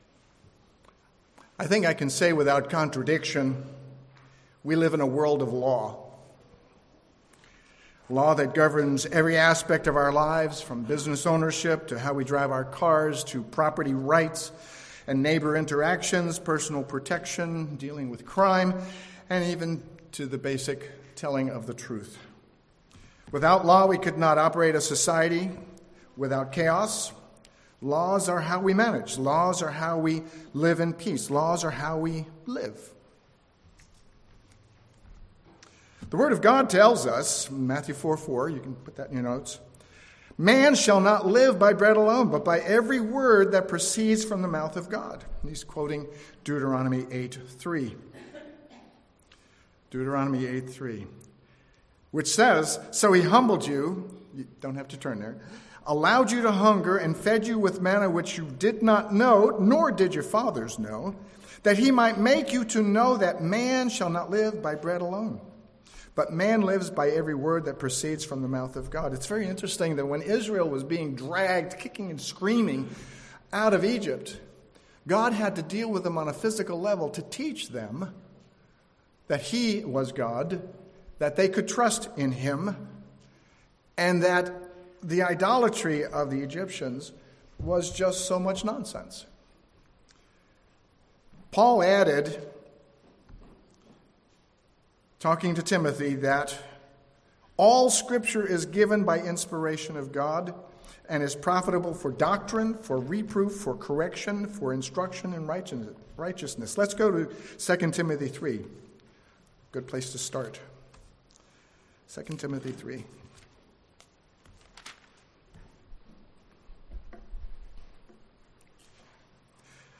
Listen to this sermon to learn what differences there are between ancient Israel and spiritual Israel, and why ancient Israel and Mosaic Law was applied to a nation as a group. There were immediate physical consequences for sin, whereas spiritual Israel, (those who follow Jesus Christ), is composed of individuals with the law written into their hearts and they must repent of sins to be saved and receive eternal life.
Given in San Jose, CA